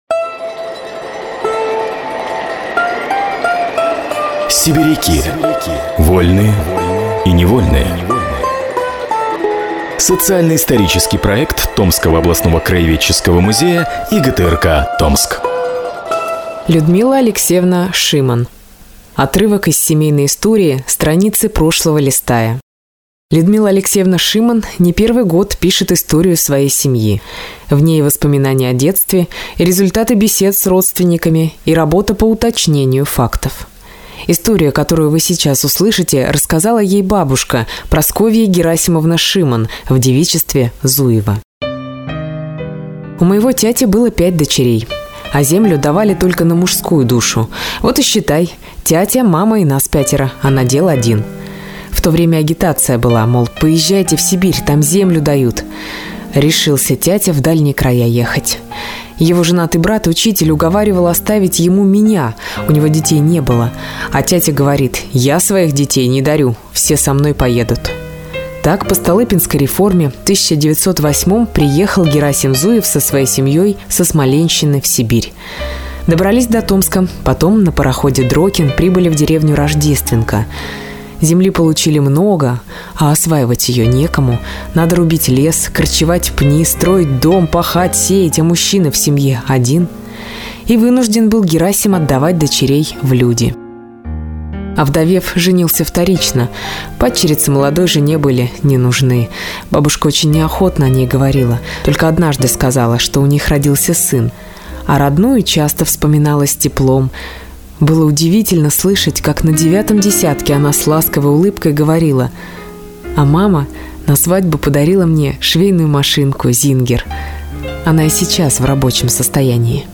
Мужской голос
Женский